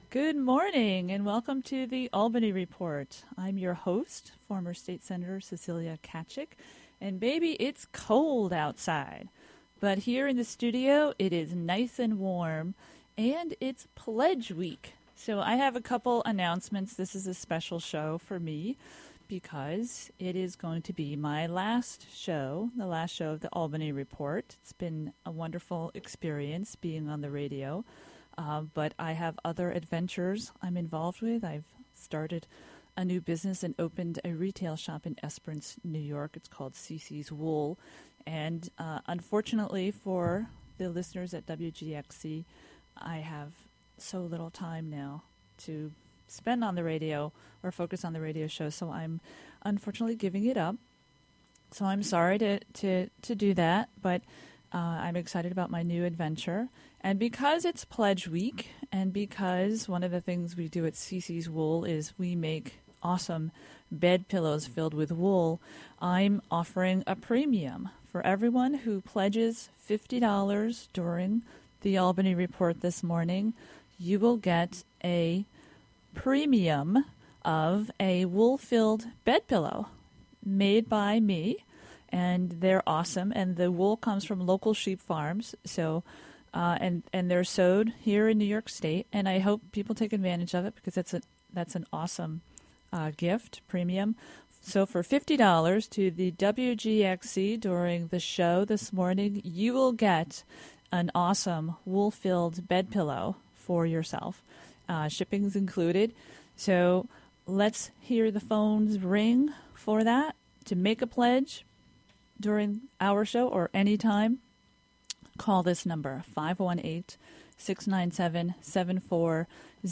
recorded live at The Linda in Albany on Feb. 8, 2016